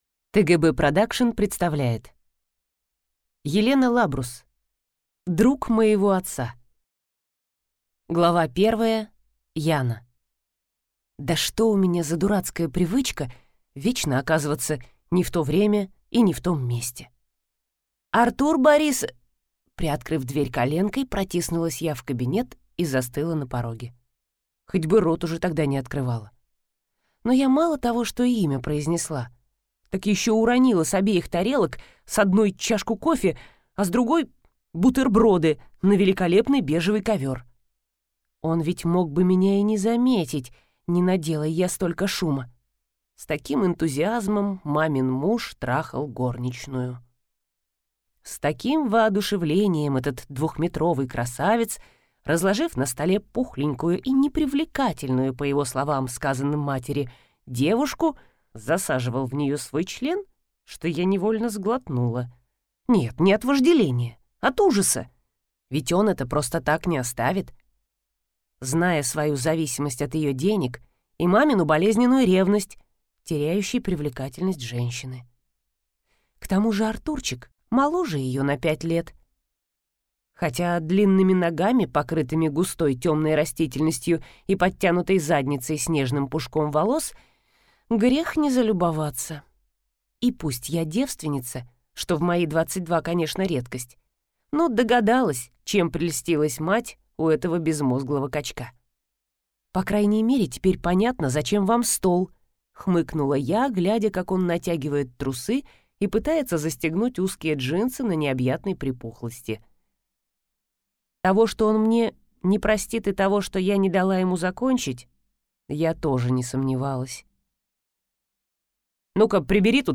Аудиокнига Друг моего отца | Библиотека аудиокниг
Прослушать и бесплатно скачать фрагмент аудиокниги